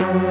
Freq-lead38.ogg